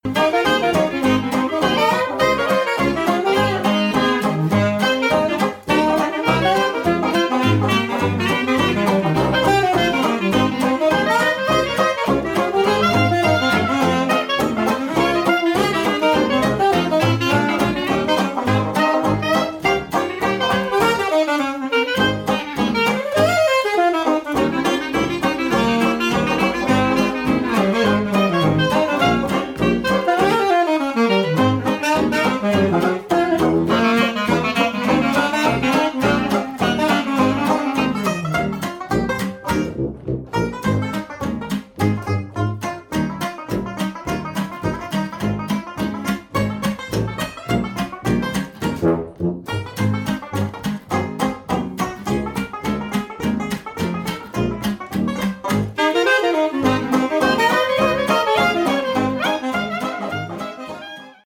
jazz hot, de ragtime et de blues
saxophone alto, clarinette
saxophone baryton
banjo, guitare ténor
chant, guitare
contrebasse